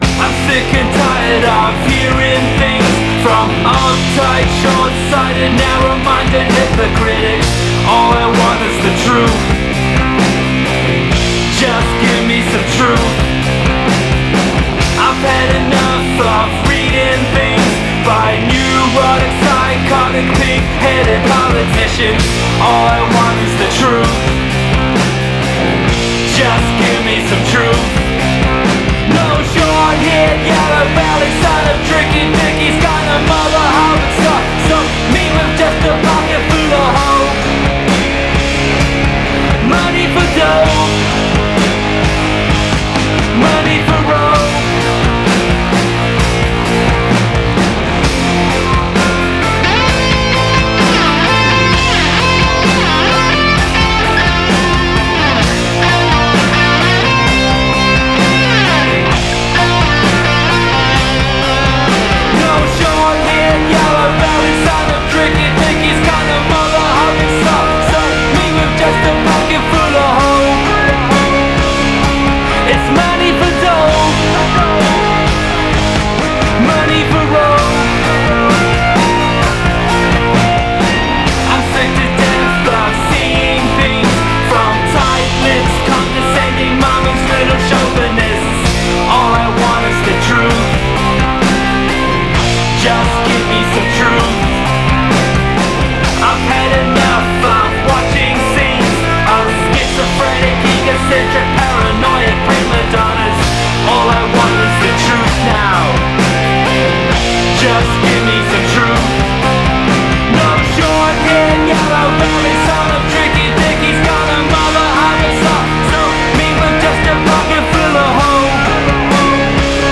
Genres : alt-rock, grunge, industrial, lo-fi, rock, synth